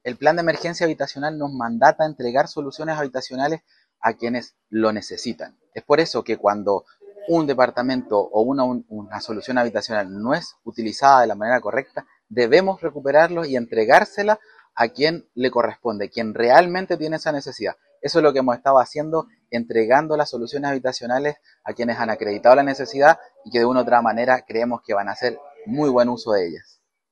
Fabián Nail, seremi regional del Minvu, remarcó que el plan habitacional busca entregar las viviendas a quienes realmente las necesitan.